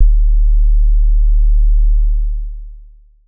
Southside 808 (18).wav